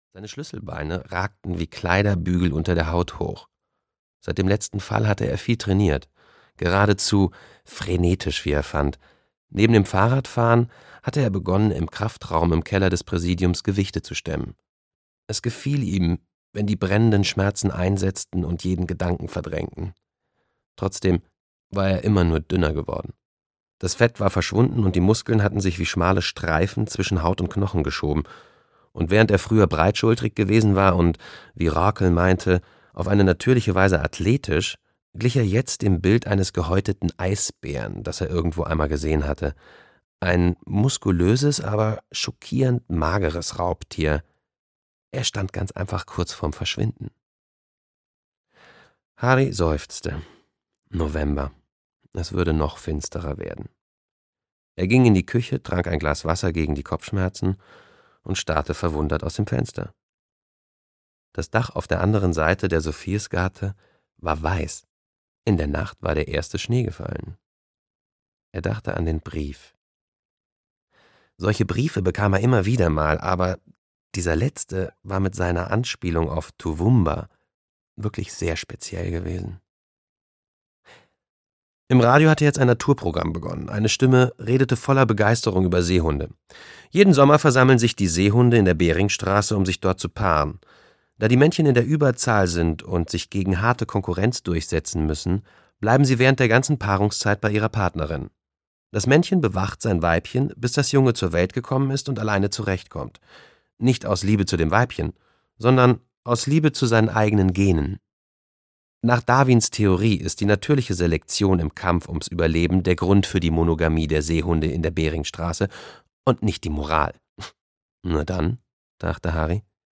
Schneemann (Ein Harry-Hole-Krimi 7) - Jo Nesbø - Hörbuch